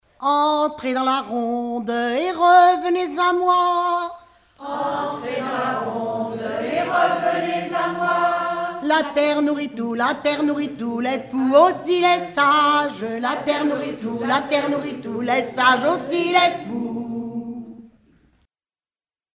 Mémoires et Patrimoines vivants - RaddO est une base de données d'archives iconographiques et sonores.
Rondes enfantines à baisers ou mariages
danse : rondes enfantines (autres)
Pièce musicale inédite